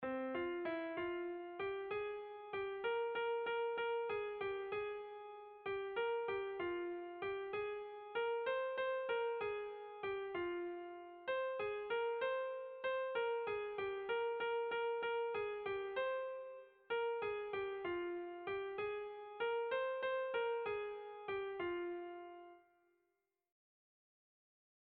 Erromantzea
Zortziko txikia (hg) / Lau puntuko txikia (ip)
ABDB